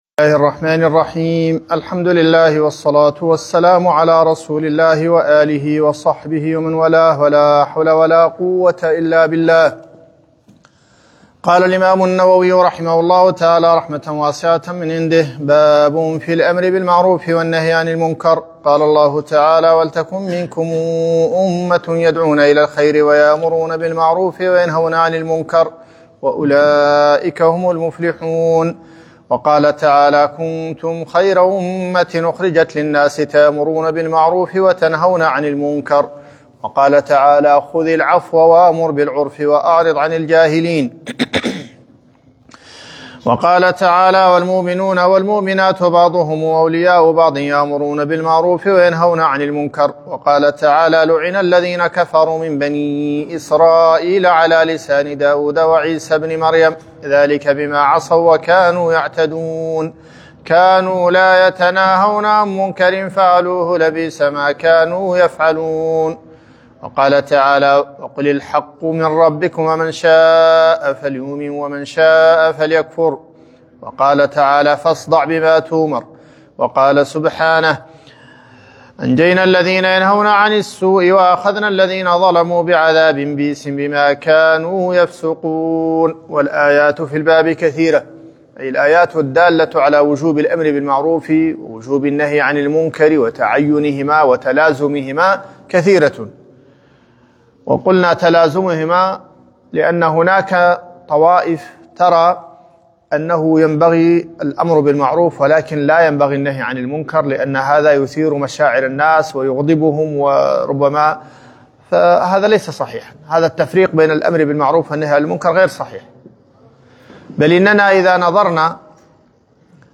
رياض الصالحين الدرس 15